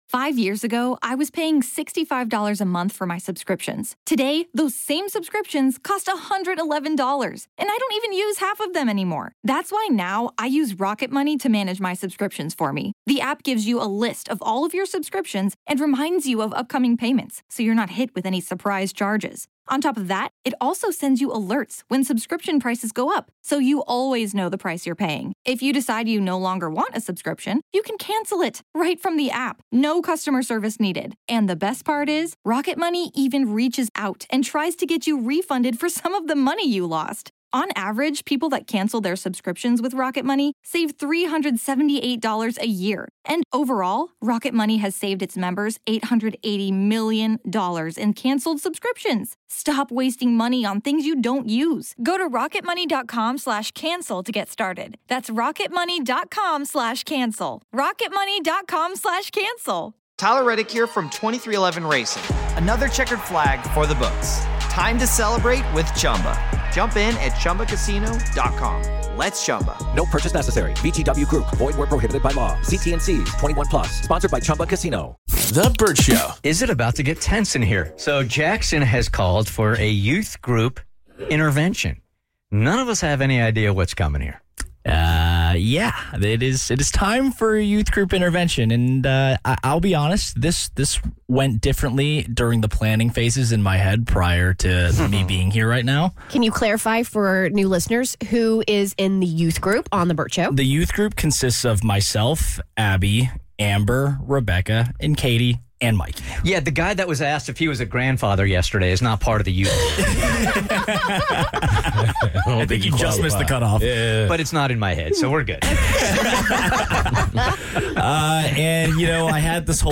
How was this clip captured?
The youth group is tired of one member's shenanigans...you might be surprised by who they're calling out live on air this time!